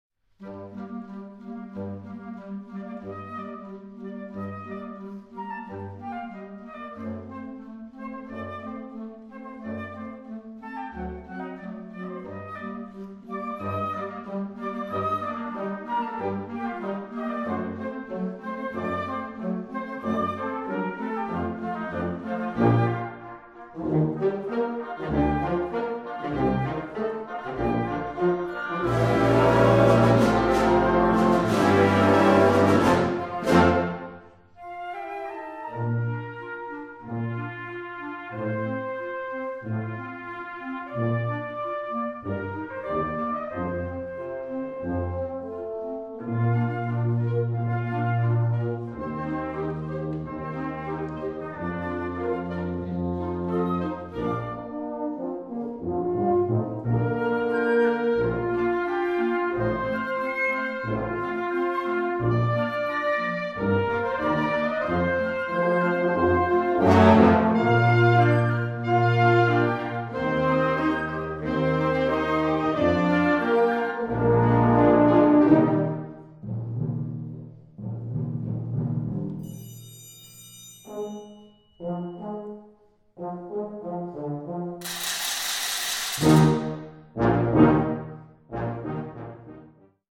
Genre: Band
Euphonium
Tuba
Percussion 1 (glockenslpiel)
Percussion 2 (snare drum, triangle, rattle)